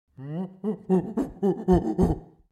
دانلود آهنگ میمون 1 از افکت صوتی انسان و موجودات زنده
دانلود صدای میمون 1 از ساعد نیوز با لینک مستقیم و کیفیت بالا
جلوه های صوتی